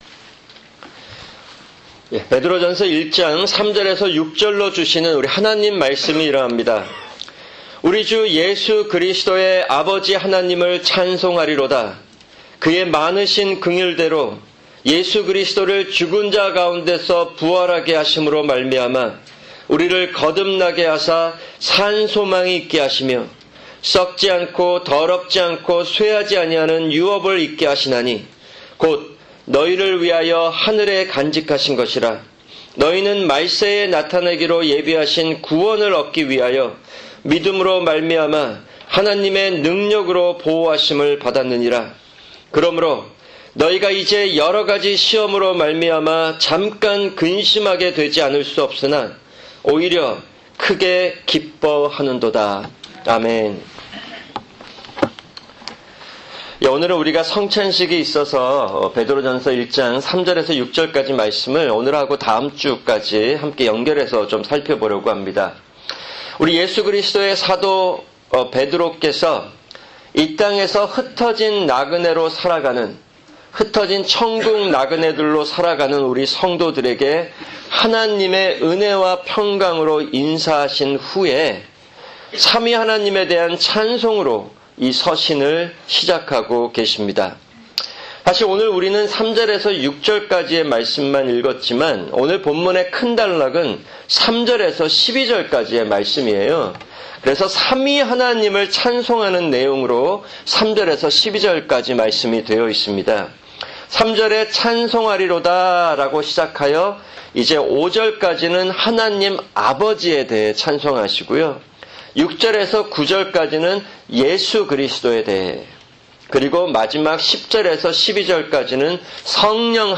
[주일 설교] 베드로전서1:3-6(1)